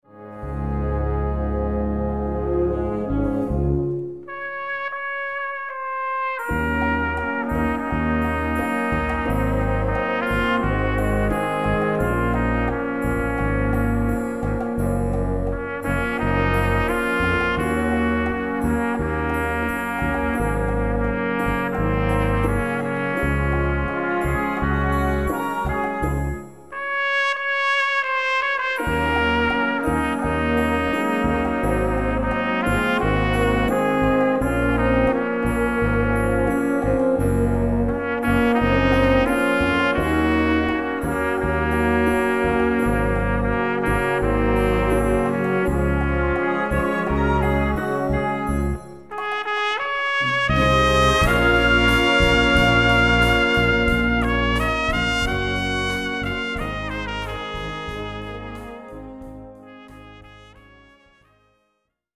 Solo für Trompete und Blasorchester
Besetzung: Blasorchester